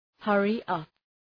hurry-up.mp3